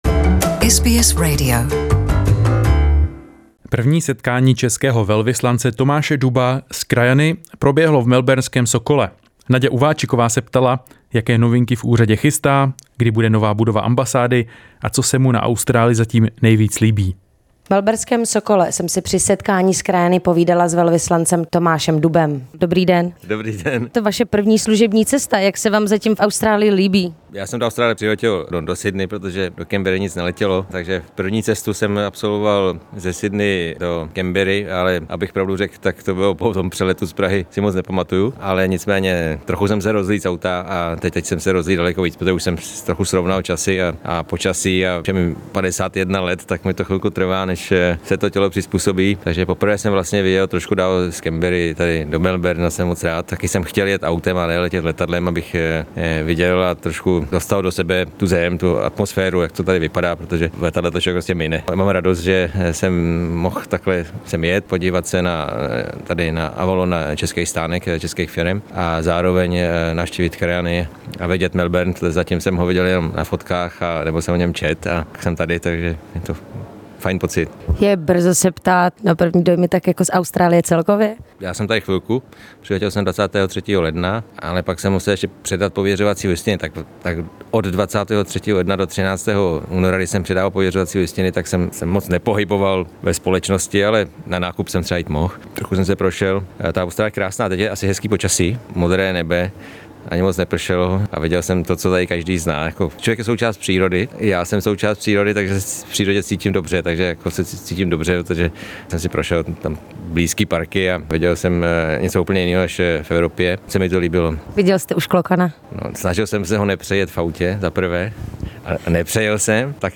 První setkání českého velvyslance Tomáše Duba s krajany proběhlo v melbournském Sokole. Jaké novinky v úřadě chystá, kdy se můžeme těšit na novou budovu ambasády a co se mu na Austrálii zatím nejvíc líbí?